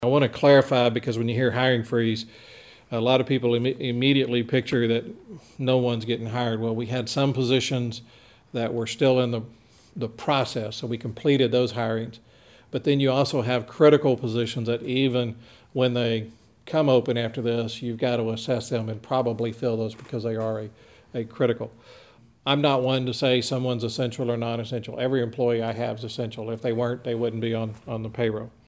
During his COVID-19 update at Thursday's meeting of the Brenham City Council, City Manager James Fisher explained that, heading into the next fiscal year, the city is looking at a drop of about $1.4 million in revenue.